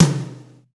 TOM XC.TOM07.wav